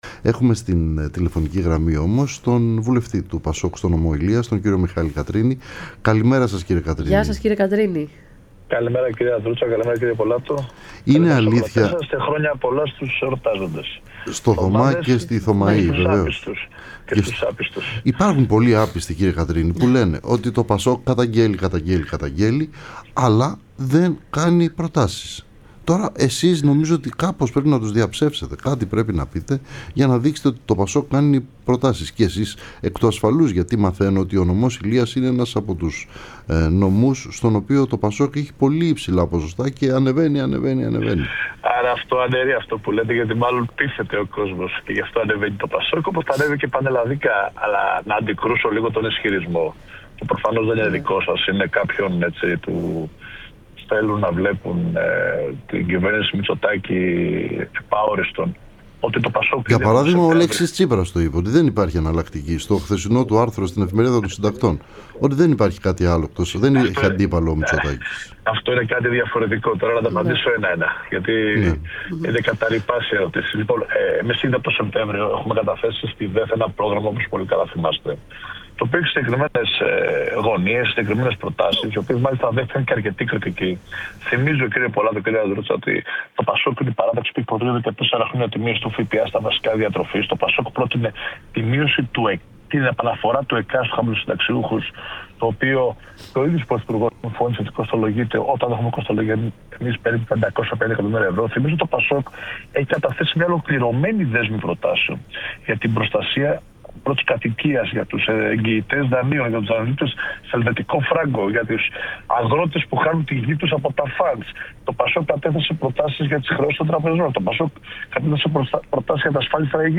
Ο Μιχάλης Κατρίνης, βουλευτής ΠΑΣΟΚ Ηλείας, μίλησε στην εκπομπή “Ραντάρ”